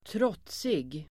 Uttal: [²tr'åt:sig]